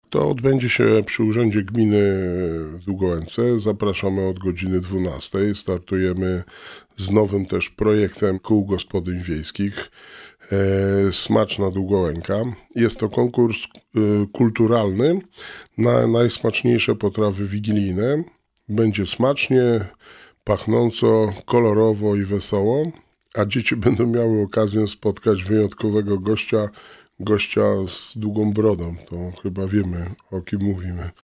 W studiu Radia Rodzina gościł Wojciech Błoński, wójt Gminy Długołęka.